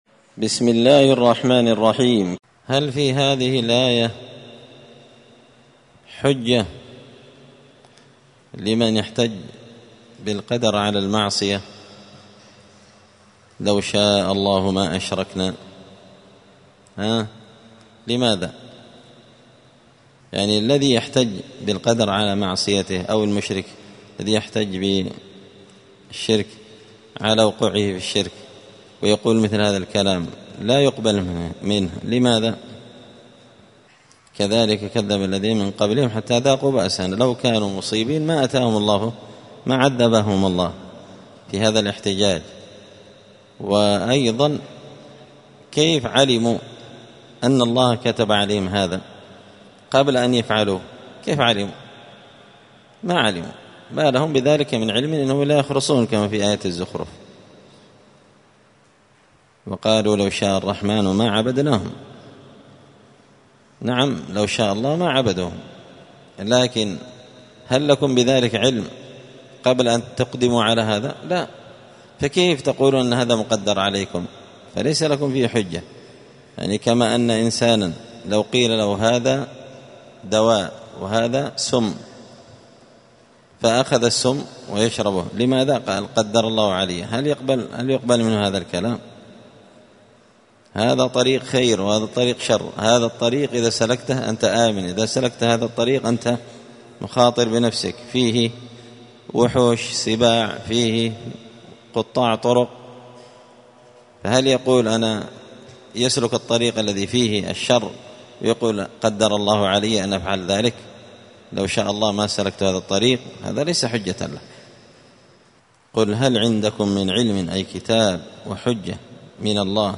📌الدروس اليومية
مسجد الفرقان_قشن_المهرة_اليمن